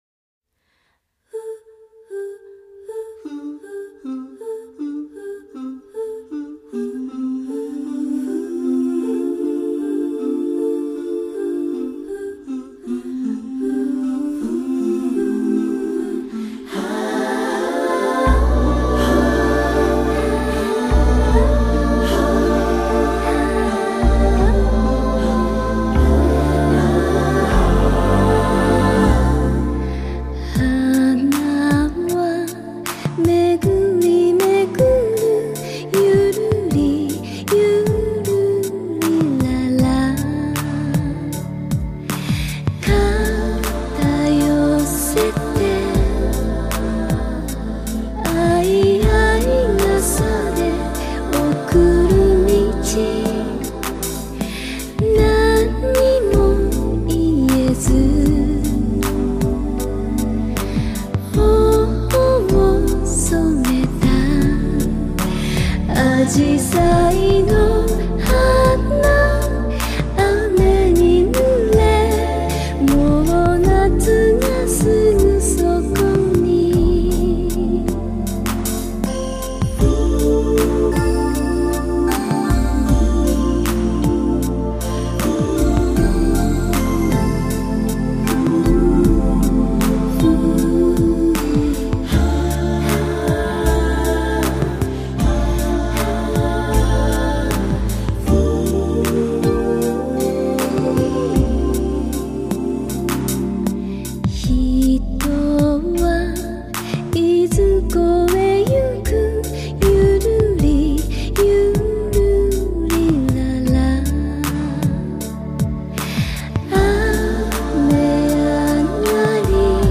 含蓄而美丽的日语，结合现代的用语和古语
时而如清风吹拂，时而如流水淙淙的人声，让闻者心情舒畅